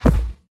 Minecraft Version Minecraft Version snapshot Latest Release | Latest Snapshot snapshot / assets / minecraft / sounds / mob / polarbear / step1.ogg Compare With Compare With Latest Release | Latest Snapshot